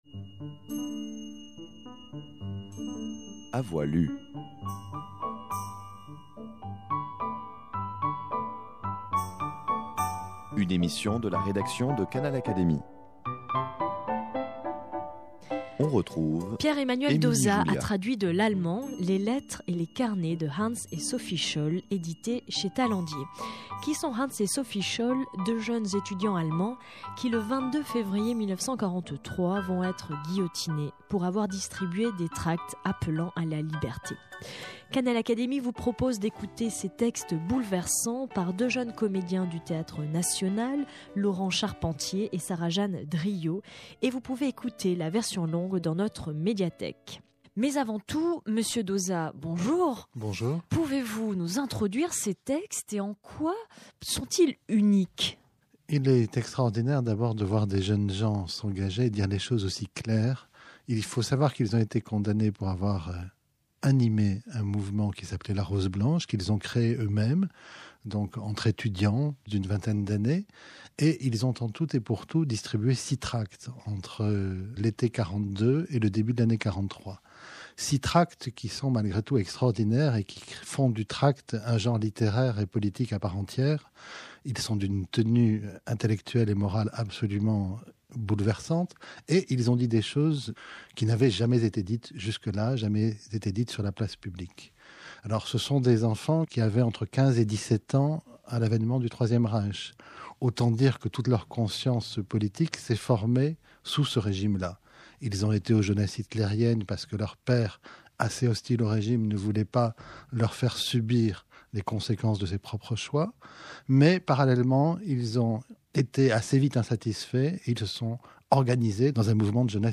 Ecoutez la lecture bouleversante de quelques extraits de ces lettres, témoignage d’un passé meurtri de blessures.
_ Canal Académie a enregistré la soirée exceptionnelle de lecture organisée par les éditions Tallandier, de lettres choisies de Hans et Sophie Scholl. Cette émission vous propose une version abrégée, ne reprenant la lecture que de quelques lettres.